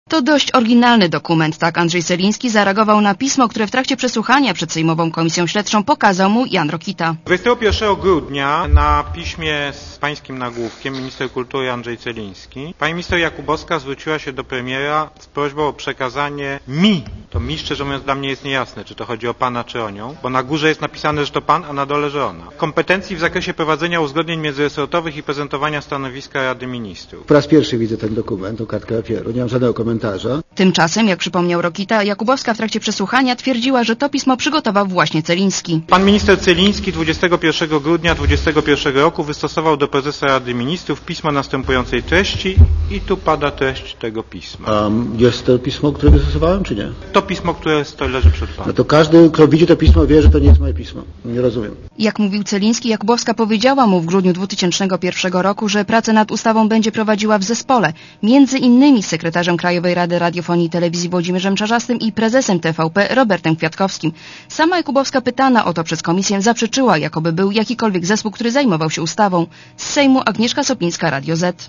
Posłuchaj relacji reporterki Radia Zet (271 KB)